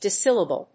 音節dis・syl・la・ble 発音記号・読み方
/dísìləbl(米国英語)/